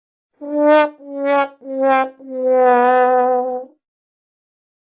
Sad Trumpet Sound Effect Free Download
Sad Trumpet